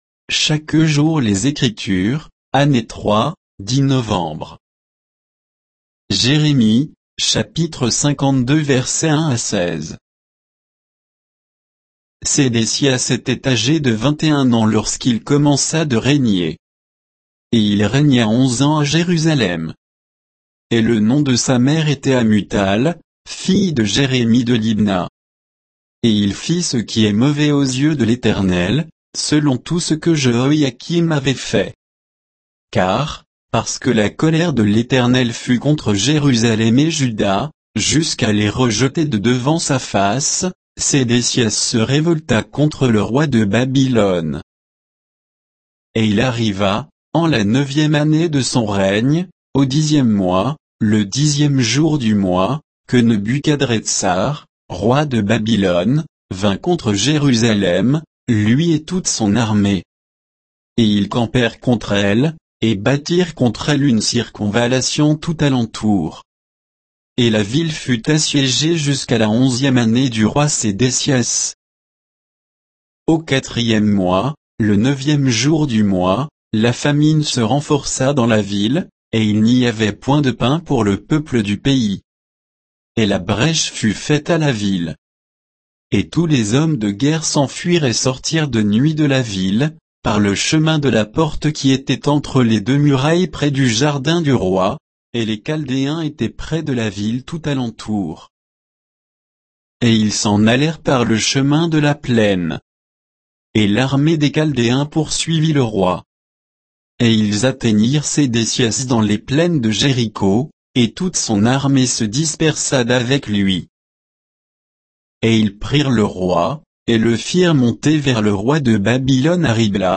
Méditation quoditienne de Chaque jour les Écritures sur Jérémie 52